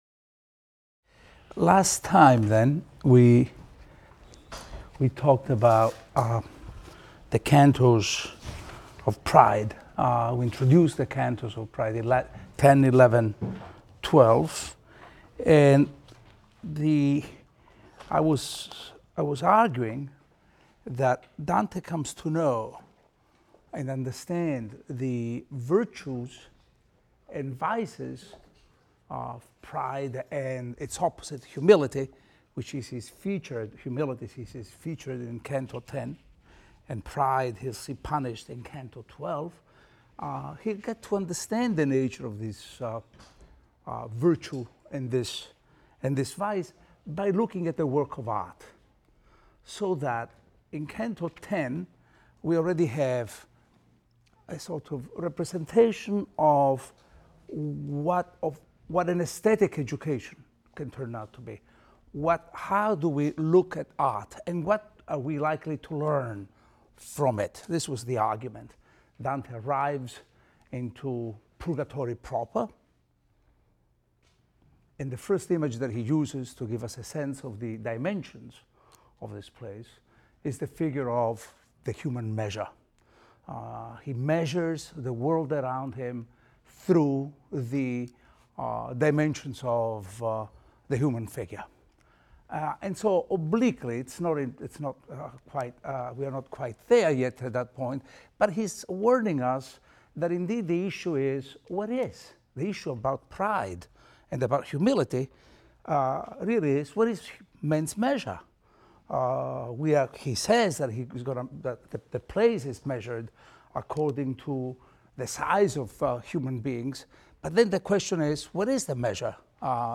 ITAL 310 - Lecture 12 - Purgatory X, XI, XII, XVI, XVII | Open Yale Courses